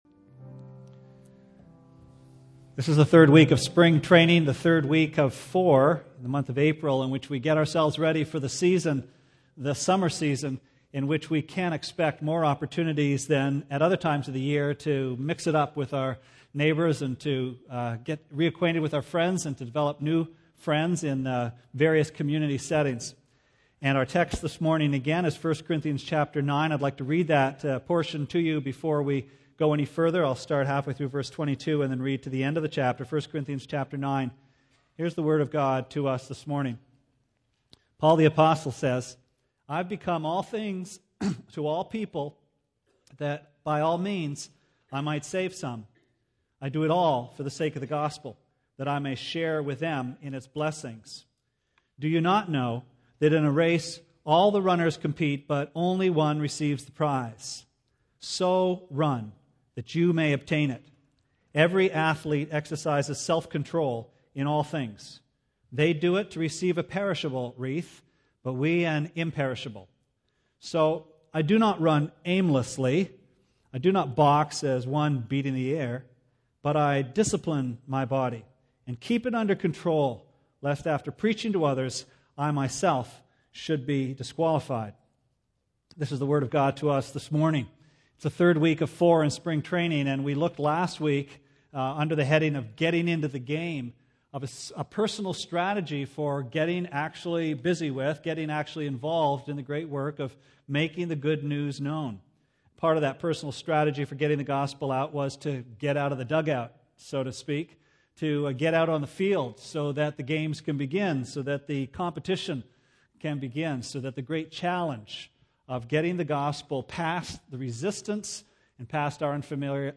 Sermon Archives Apr 20